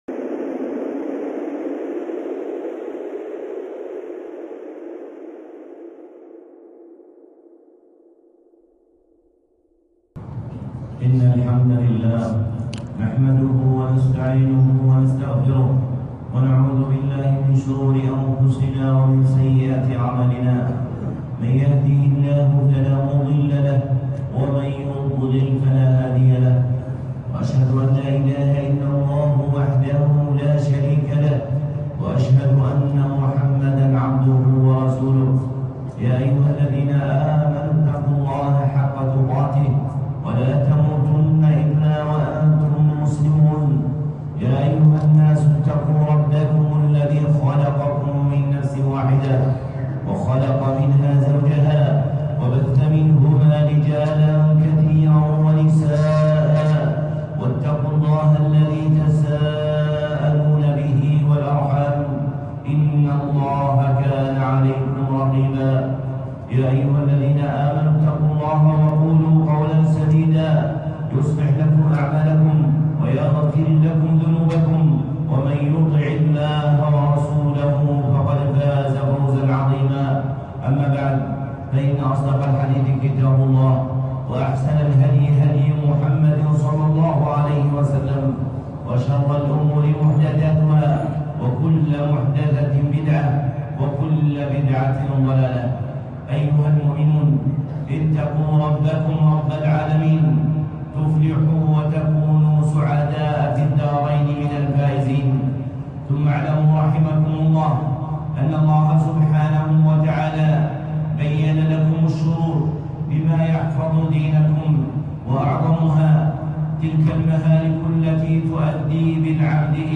خطبة (تحذير البرية من شعبة سحرية)